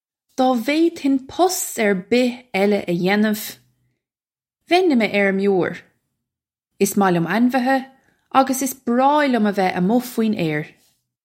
Pronunciation for how to say
Daw vade-hin pust urr bih ella uh yay-nuv, vay-in im errim-yore. Iss moh lyum an-veeha uggus iss braw lyum uh veh a-mwig fween air
This is an approximate phonetic pronunciation of the phrase.